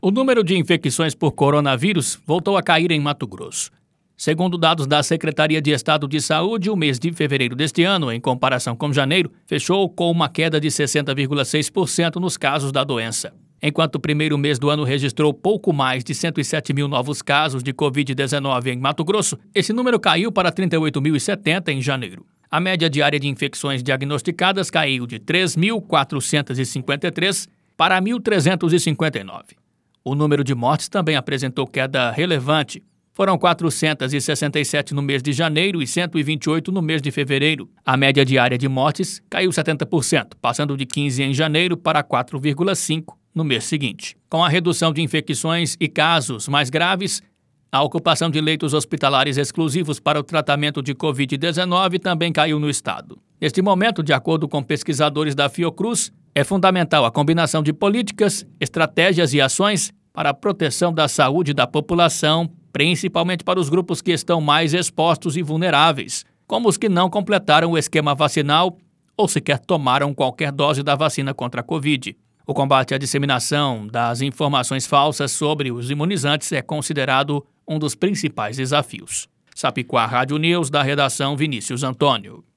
Boletins de MT 03 mar, 2022